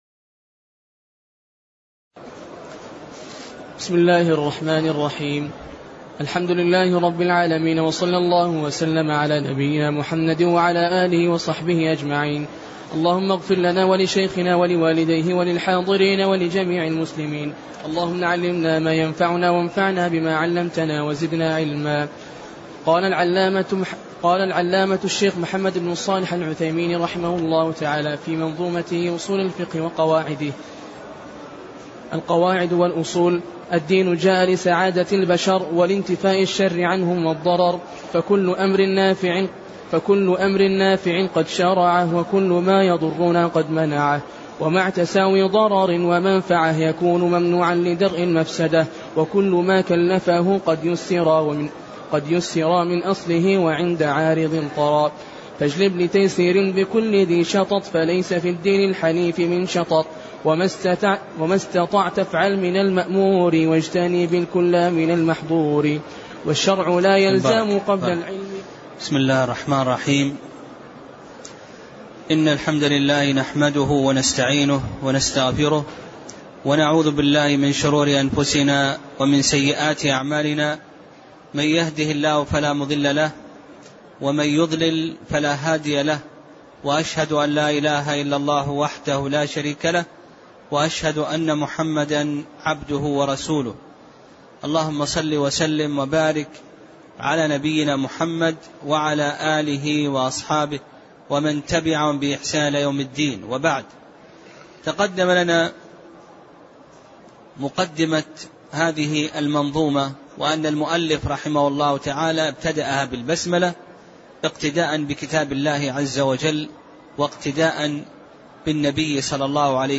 تاريخ النشر ٣٠ رجب ١٤٣٤ هـ المكان: المسجد النبوي الشيخ